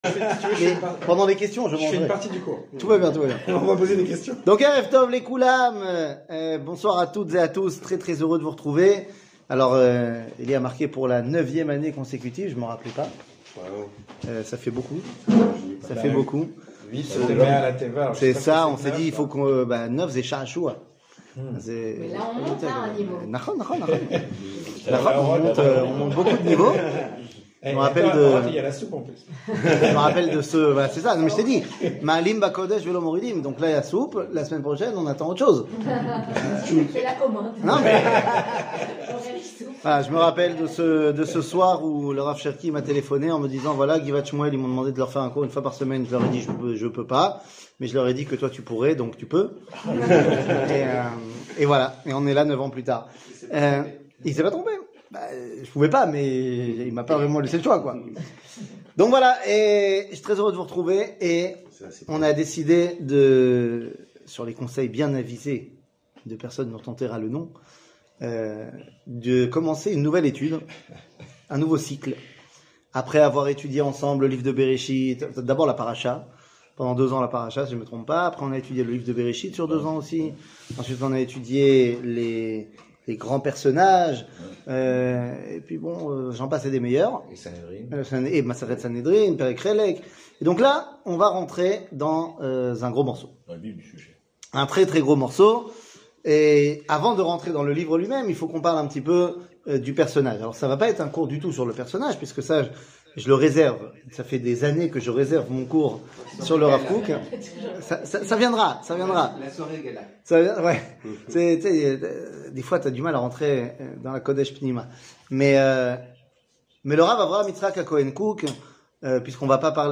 שיעור